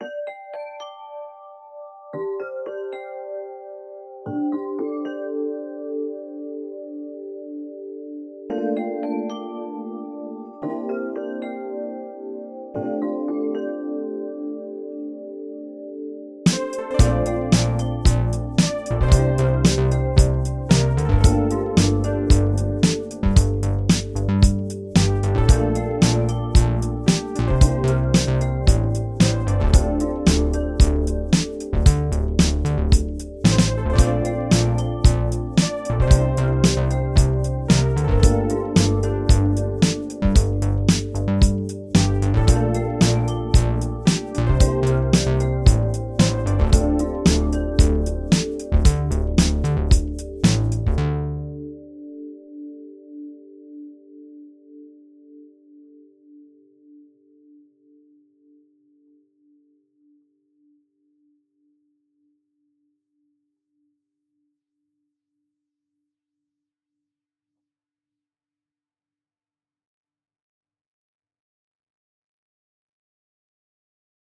BRRRING, BRRRING!
We went out for a walk — on bicycle lanes — to record the bells of furious bike drivers.
audio_bikebells.mp3